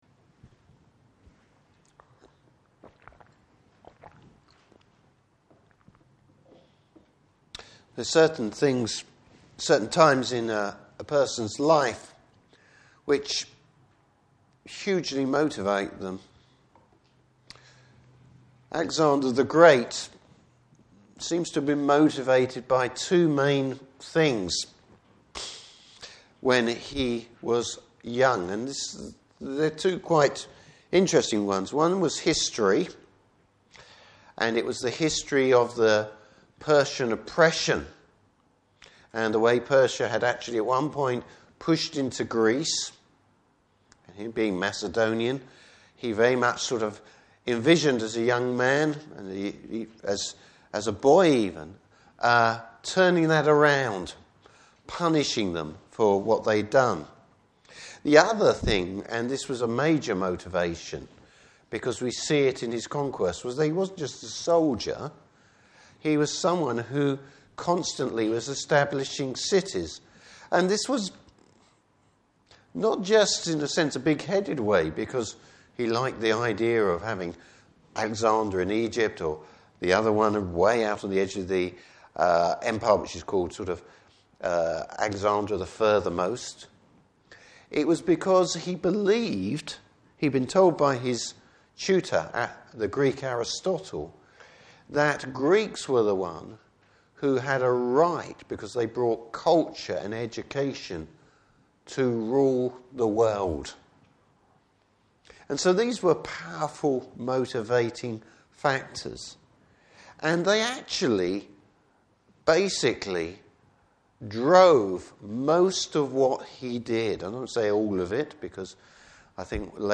Service Type: Evening Service Bible Text: Revelation 18.